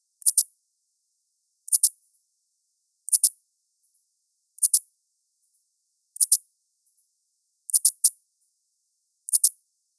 currant shieldback
10 s of calling song and waveform. Nye County, Nevada; 21.0°C. R09-211.